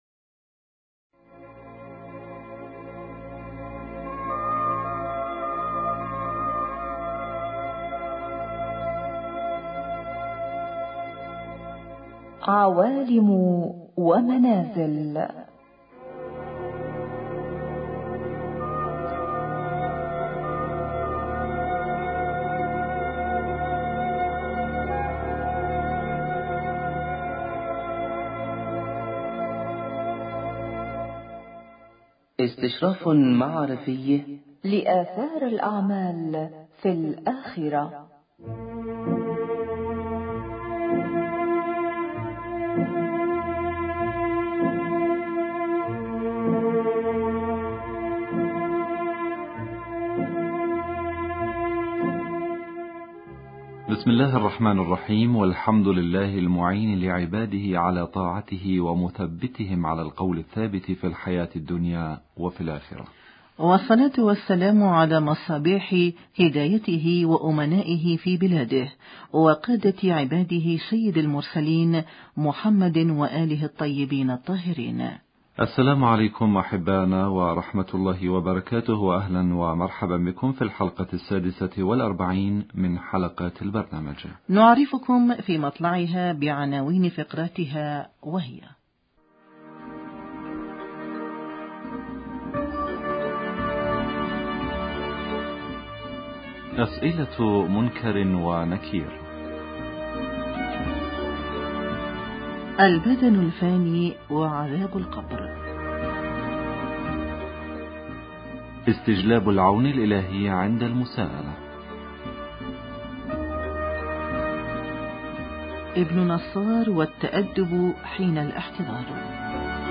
حياة القبر اسئلة منكر ونكير حوار